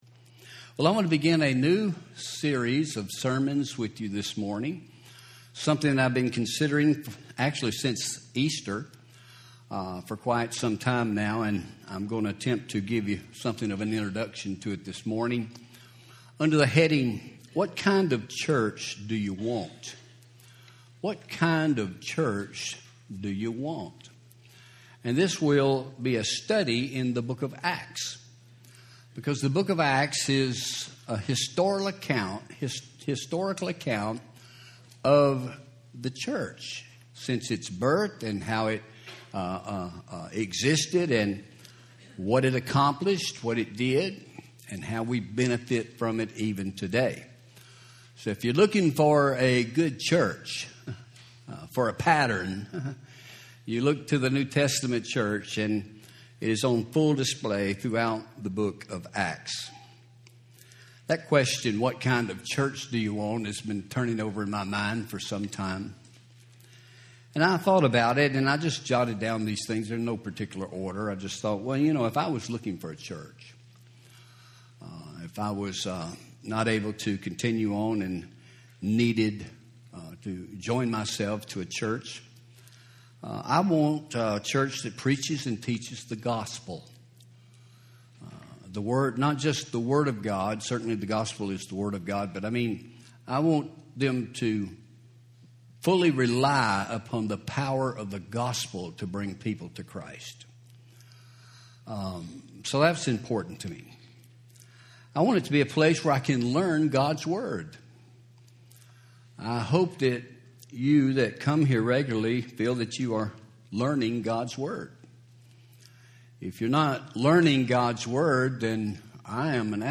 Home › Sermons › What Kind of Church Do You Want